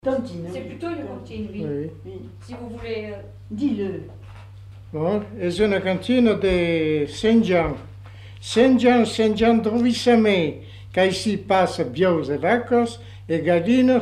Lieu : Arrigas
Effectif : 1
Type de voix : voix d'homme
Production du son : récité
Classification : formulette enfantine